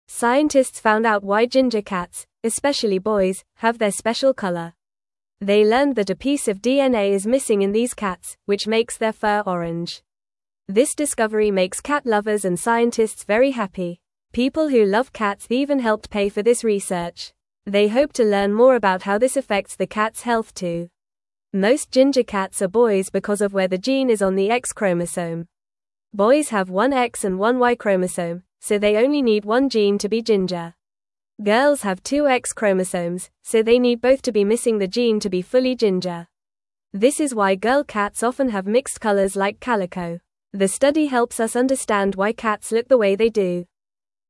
Fast
English-Newsroom-Beginner-FAST-Reading-Why-Ginger-Cats-Are-Mostly-Boys-and-Orange.mp3